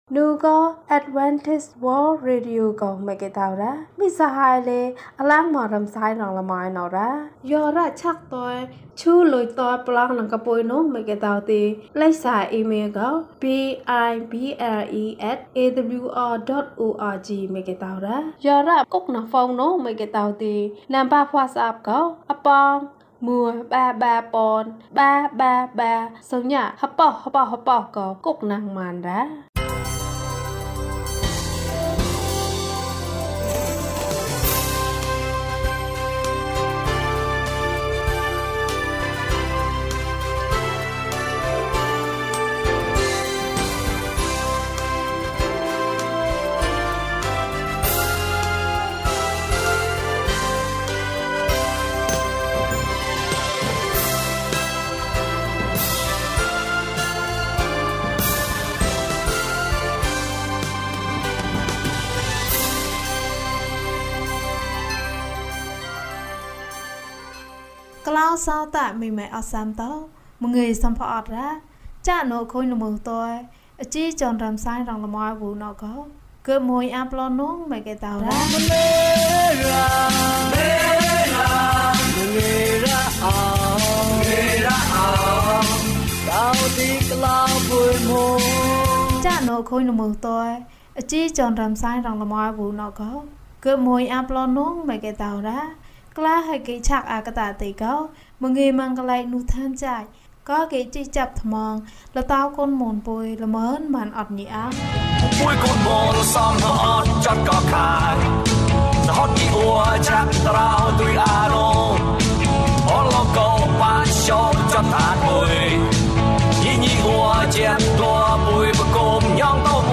ဘုရားသခင်ထံမှကောင်းကြီးမင်္ဂလာ။၂၇ ကျန်းမာခြင်းအကြောင်းအရာ။ ဓမ္မသီချင်း။ တရားဒေသနာ။